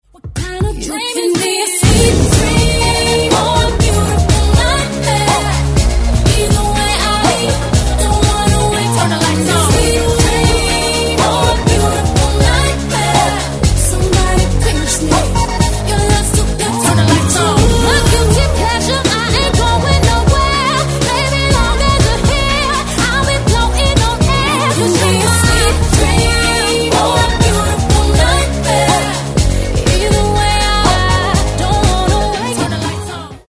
Категория: Rap, RnB, Hip-Hop